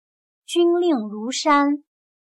军令如山/jūnlìng rúshān/Las órdenes militares son extremadamente serias, inquebrantables y cambiantes como una montaña.